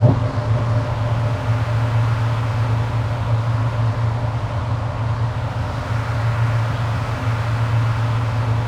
ATMOPAD23 -LR.wav